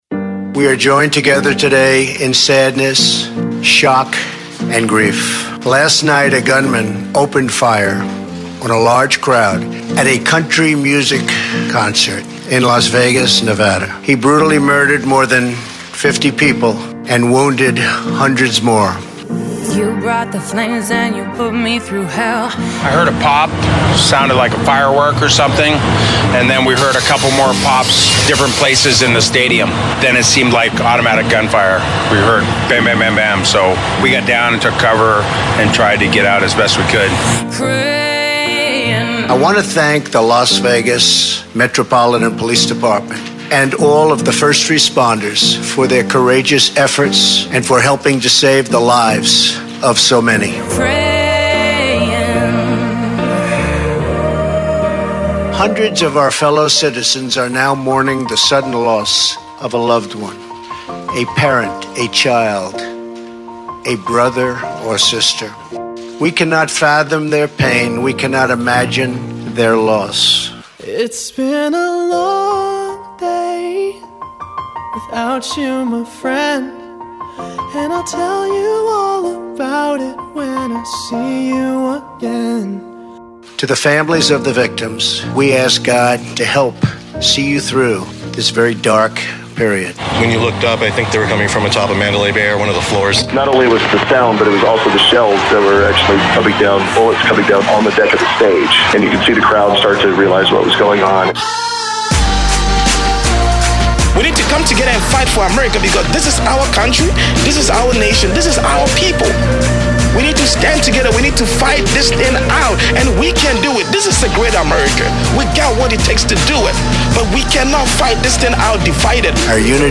In honor of the horrible tragedy that took 58 lives and injured more than 500, we produced a tribute song. This song includes audio from the event as well as president Trump's statement to the nation.
P-LasVegas-Shooting-Tribute-Montage-Pop-Version.mp3